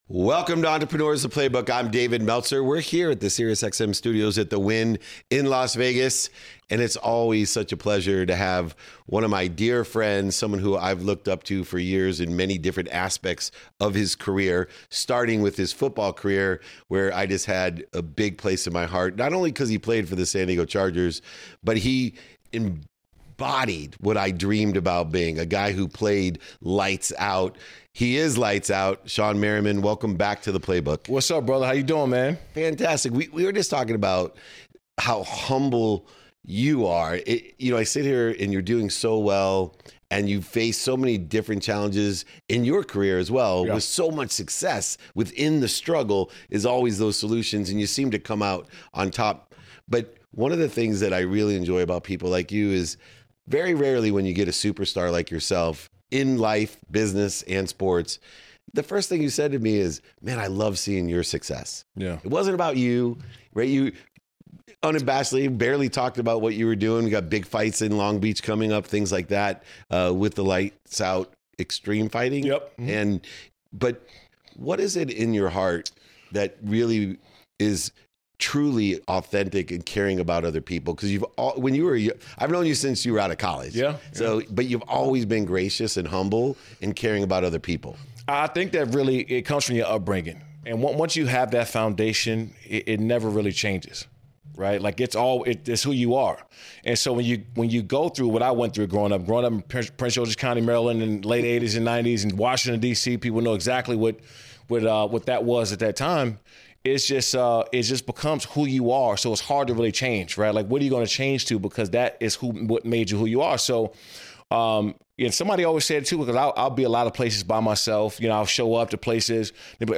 In today’s episode, I sit down with former NFL star Shawne Merriman to talk about the lessons that shaped his life on and off the field. From growing up homeless in Maryland to becoming one of the most feared linebackers in the league, Shawne explains how hardship built his character and fueled his drive. We discuss his “Lights Out” persona, the transition from football to entrepreneurship, and how he turned his passion for MMA and media into a thriving business.